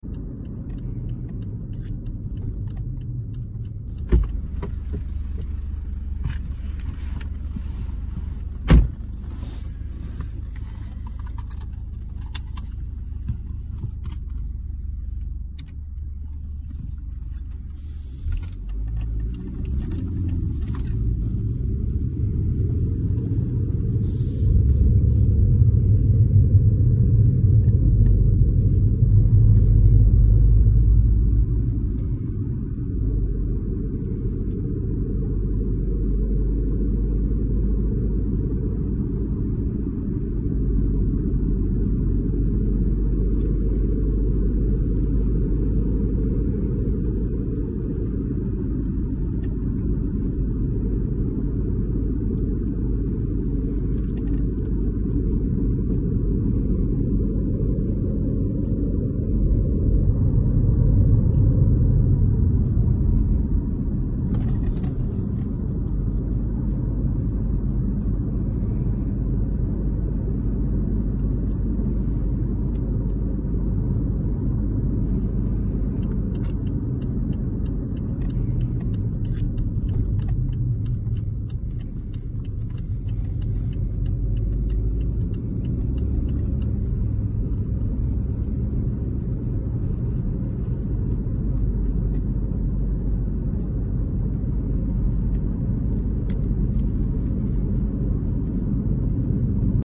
ASMR Lofi Car ride sounds sound effects free download
ASMR Lofi Car ride sounds 🚗 do you like car sounds?